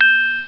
Chime Higher Sound Effect
Download a high-quality chime higher sound effect.
chime-higher.mp3